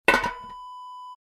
Glass jar lid close sound effect .wav #5
Description: The sound of closing a metal lid of a glass jar
Properties: 48.000 kHz 16-bit Stereo
A beep sound is embedded in the audio preview file but it is not present in the high resolution downloadable wav file.
Keywords: glass, jar, container, lid, metal, close, closing
glass-jar-lid-close-preview-5.mp3